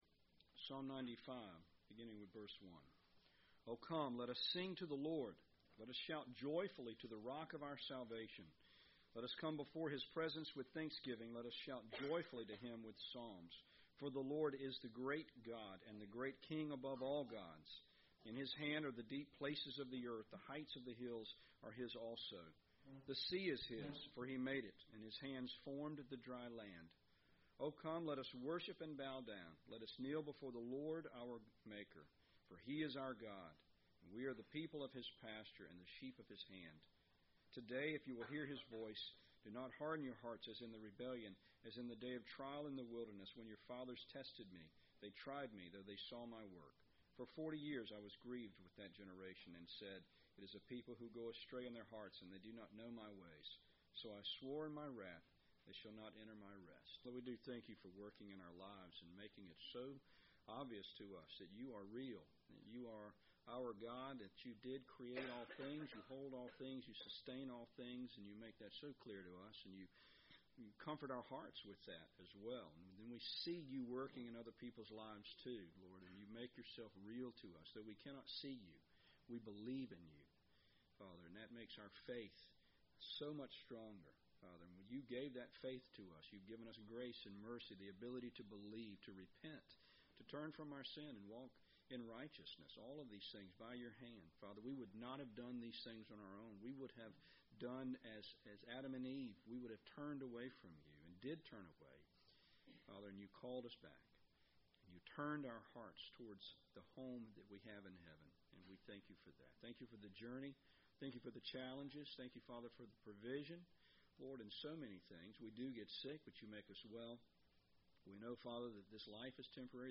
Teaching on the aforementioned subjects from the 1689 London Baptist Confession.